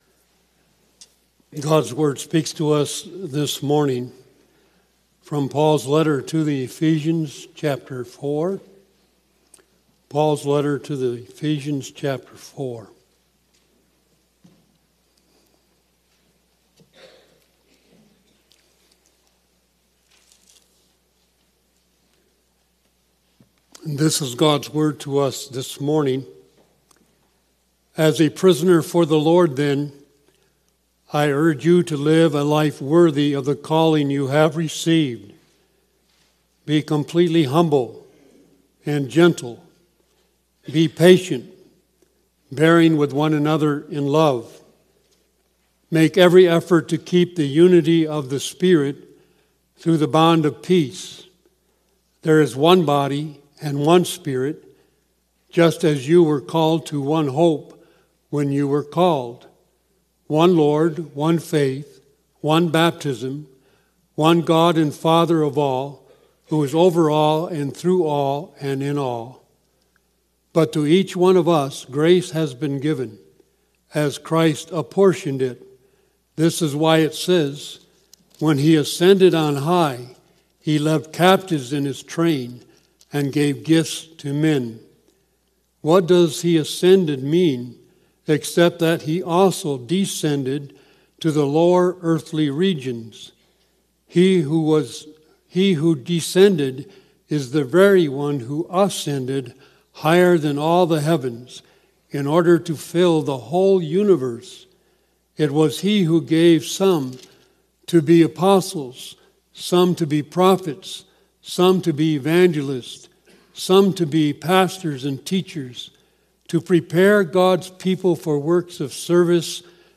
Sermon Recordings | Faith Community Christian Reformed Church
“He Took Many Captives” June 1 2025, A.M. Service